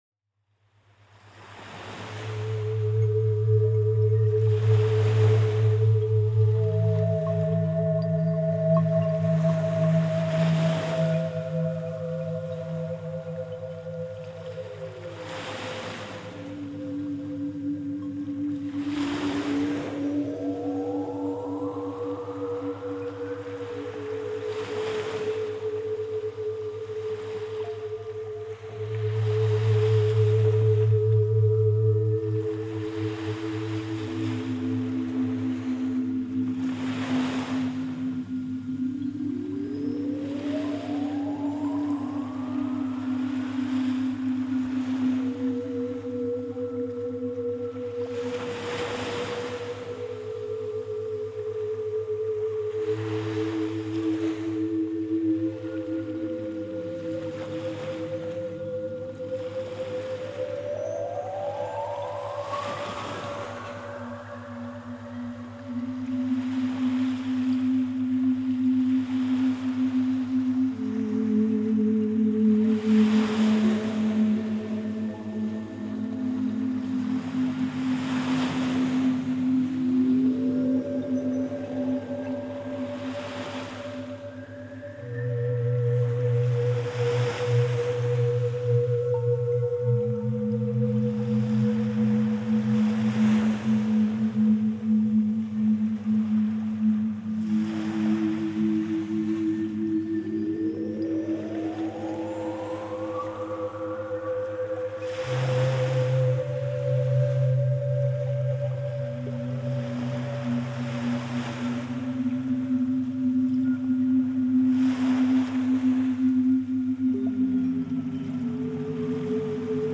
Vos clients sont invités à plonger dans un état de relaxation profonde en se laissant guider à travers une séance de méditation immersive.